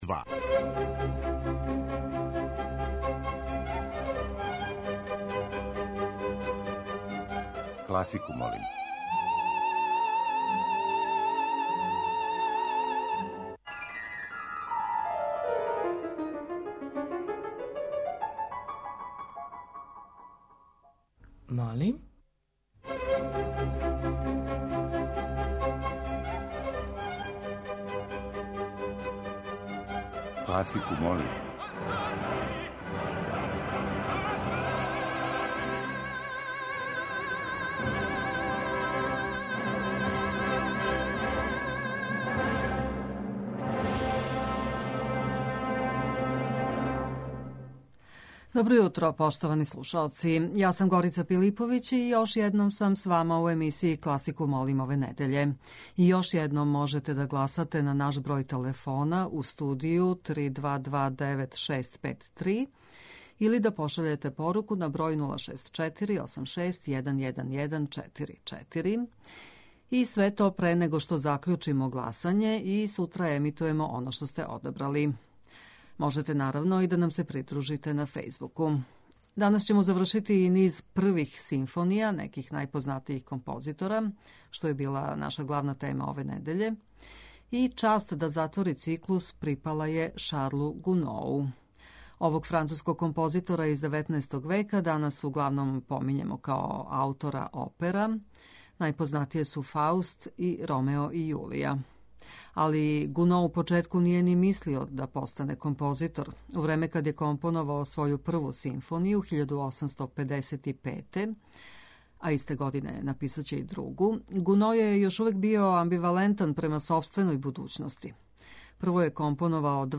Прве симфоније неких најпознатијих композитора